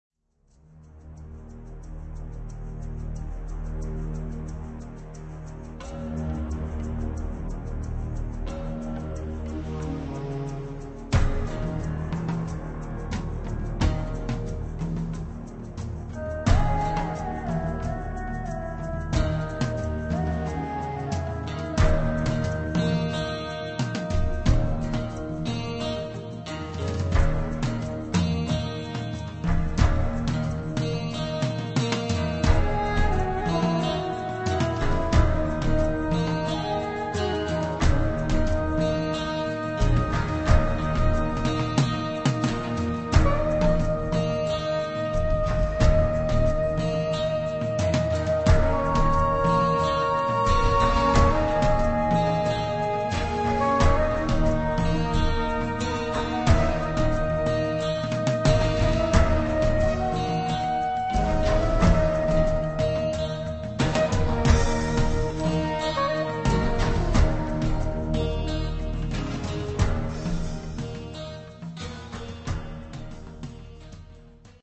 è un brano percussivo dal forte sapore medio orientale